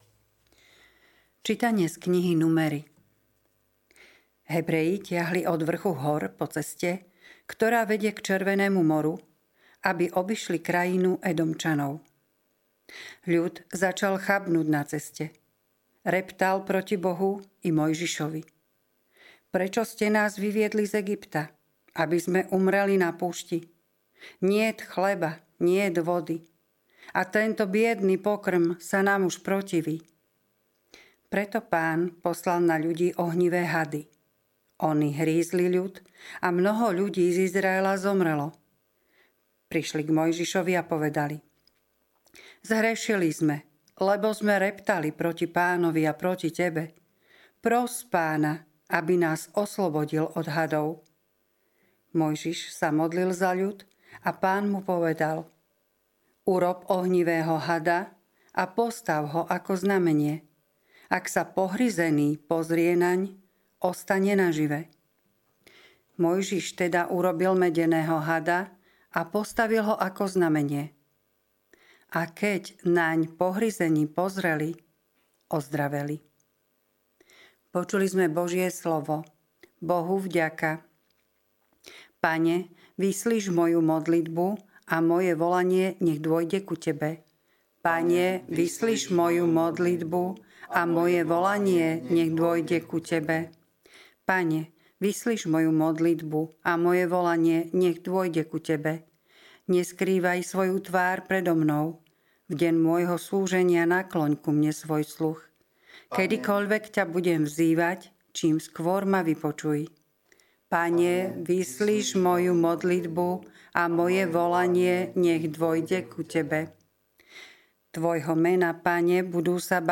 LITURGICKÉ ČÍTANIA | 8. apríla 2025